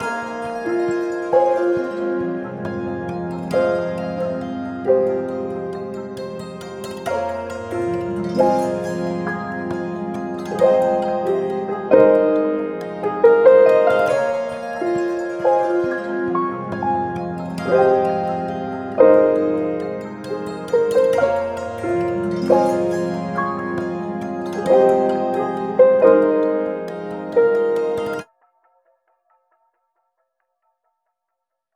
14 Trap Loops created in the style of: Drake, Future, Travis Scott, A$ap Ferg, 21 Savage, Lil Baby, 2 Chainz, Juice WRLD and More!
Perfect for Trap, but works well with R&B, Pop and similar genres too.
• High-Quality Trap Samples 💯